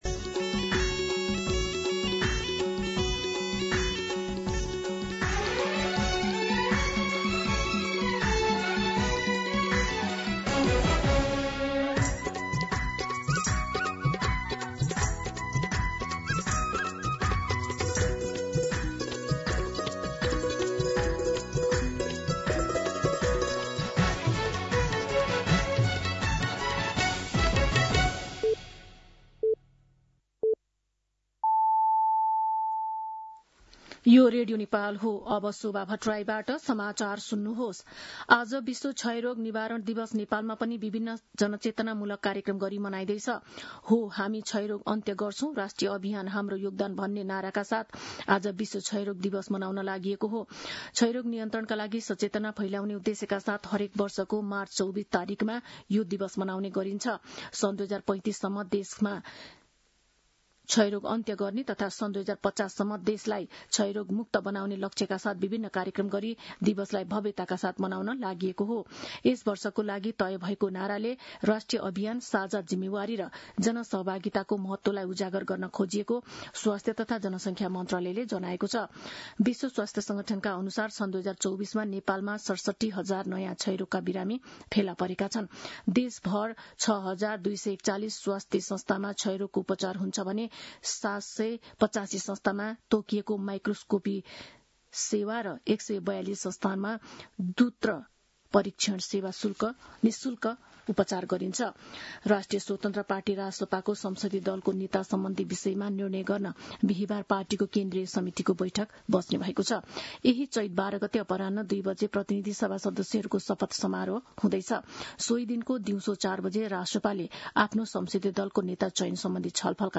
मध्यान्ह १२ बजेको नेपाली समाचार : १० चैत , २०८२